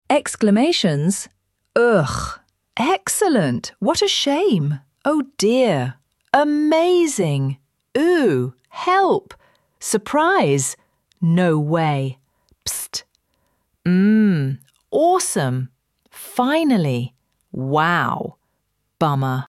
ElevenLabs_Text_to_Speech_audio-1.mp3